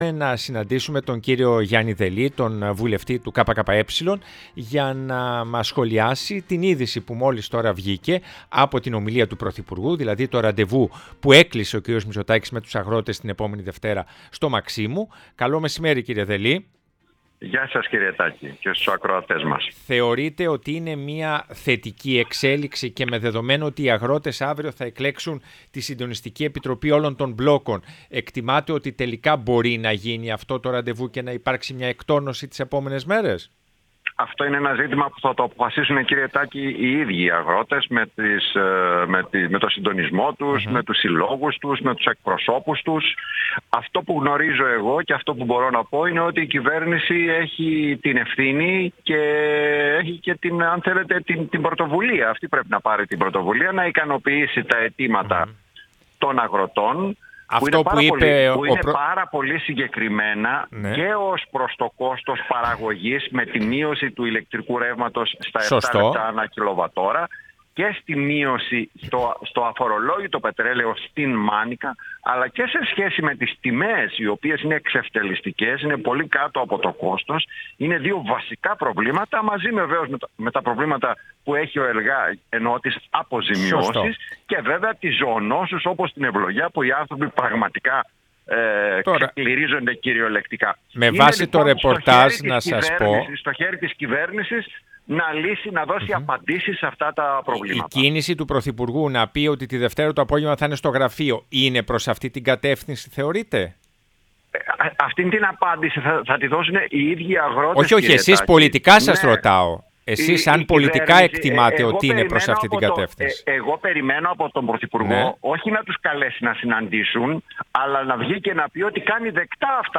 Ο Γιάννης Δελής, βουλευτής ΚΚΕ μίλησε στην εκπομπή Ναι μεν αλλά